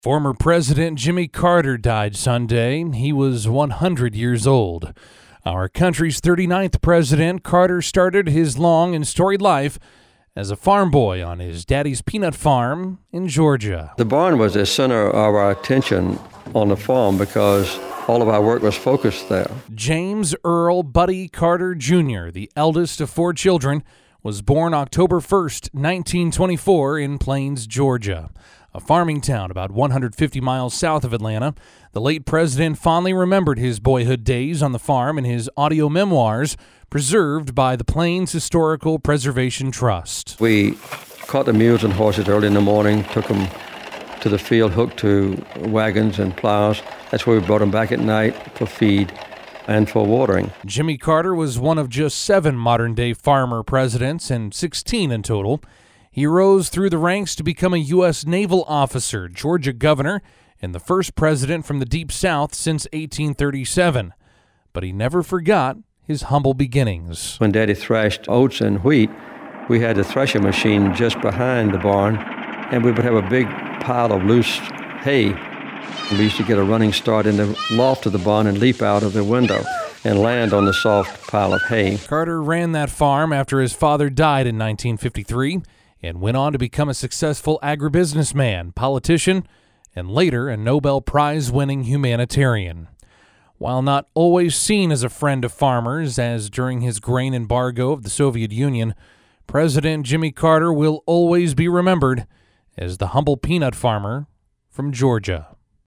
The late President fondly remembered his boyhood days on the farm in his audio memoirs, preserved by the Plains Historical Preservation Trust.